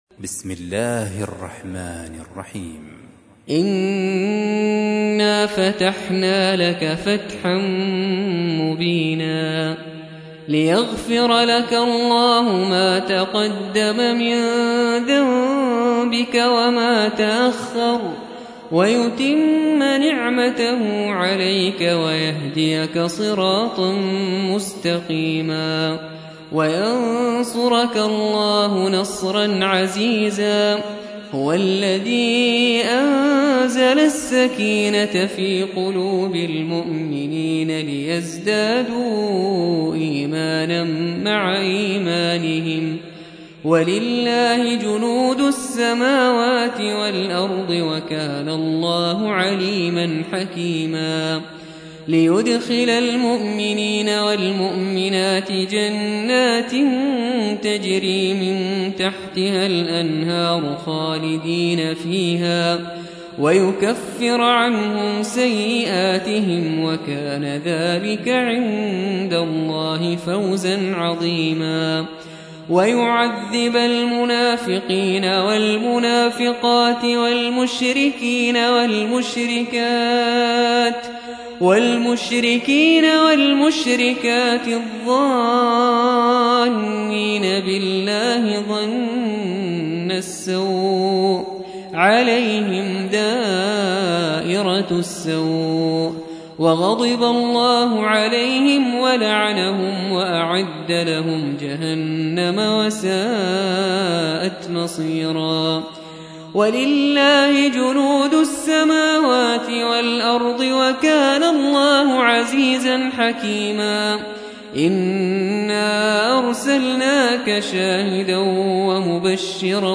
48. سورة الفتح / القارئ